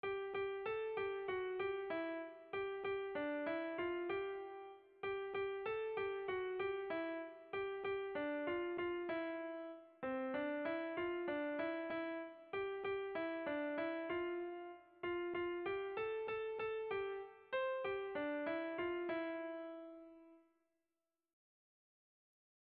Bertso melodies - View details   To know more about this section
Irrizkoa
A1A2BD